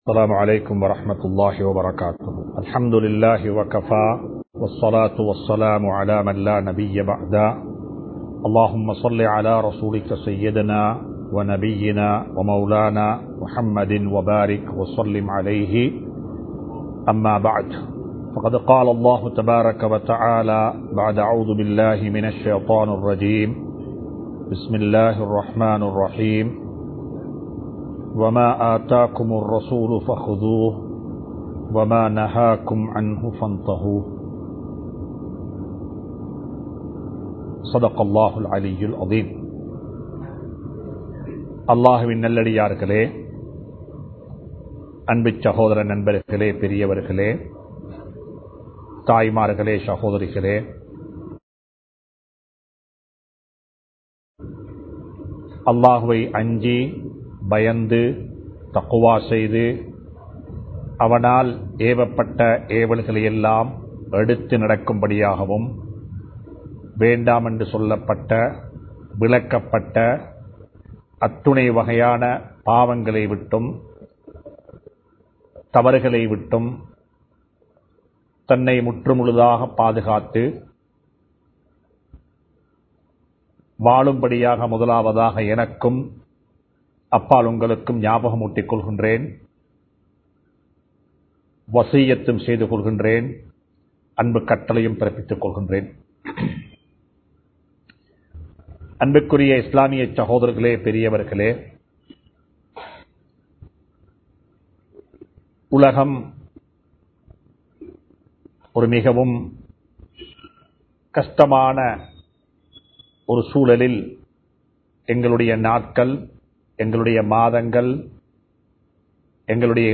எங்களது சமூகக் கடமைகள் | Audio Bayans | All Ceylon Muslim Youth Community | Addalaichenai
Kollupitty Jumua Masjith